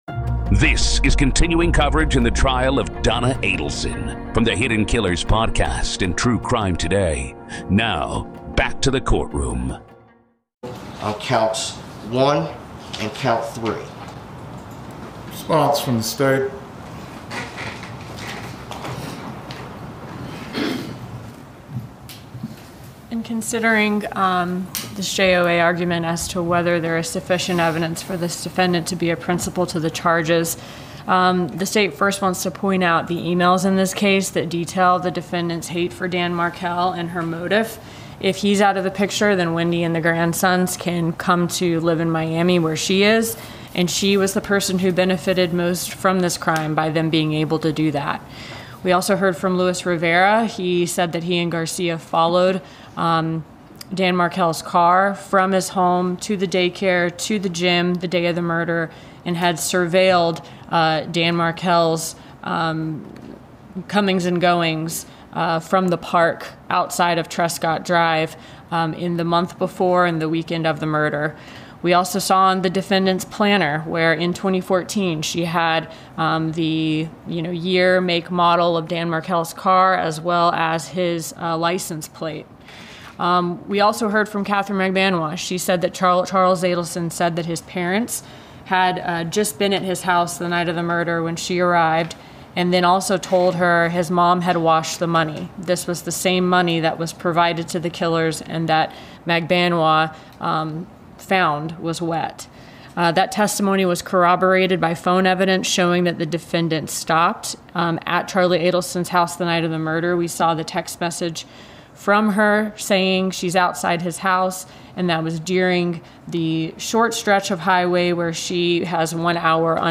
The clip captures the judge’s ruling in real time, marking a turning point in the trial.